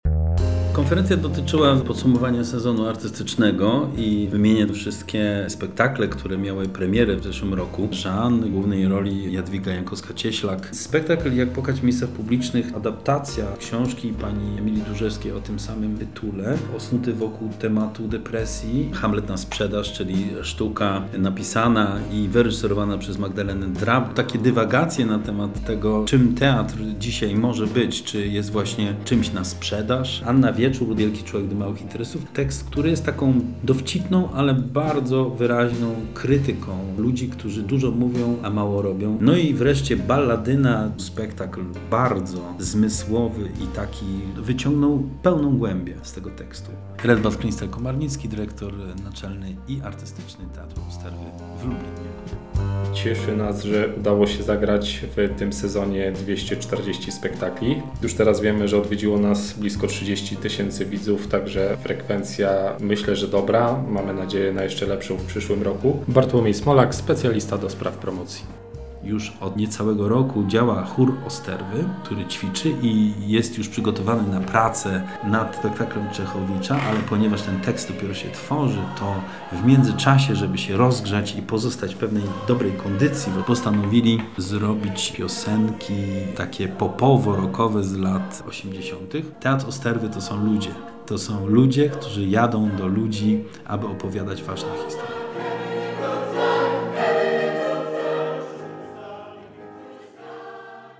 O tym, co pojawiło się w tym roku na afiszu, mówi Ekipa Teatru Osterwy w Lublinie.